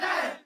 crowdDon4.wav